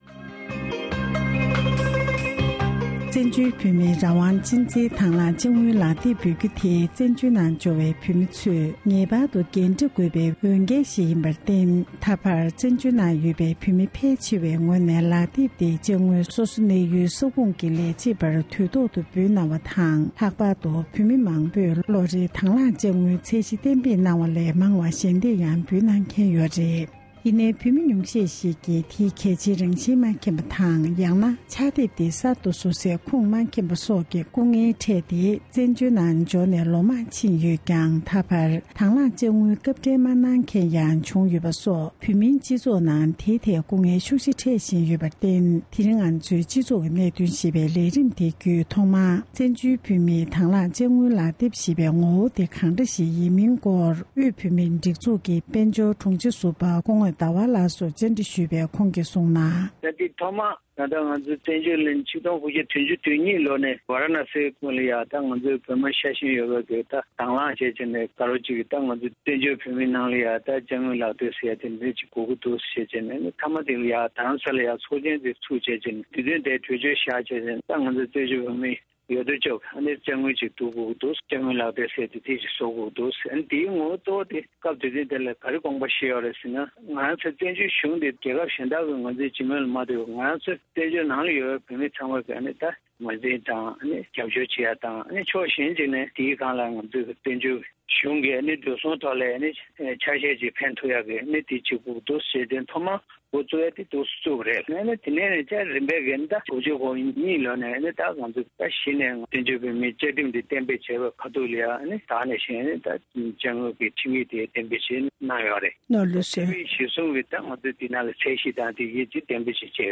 ཞལ་པར་བརྒྱུད་ཐད་ཀར་གནས་འདྲི་ཞུས་པ་ཞིག་གསན་རོགས་གནང་།